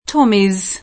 Tommy [ingl. t0mi] pers. m. (= Tommaso) — come personificaz. del soldato inglese, anche con t- minusc.; pl. ingl. tommies [
t0mi@]; inoltre, anche con pn. italianizz. [t0mmi], e in tal caso invariabile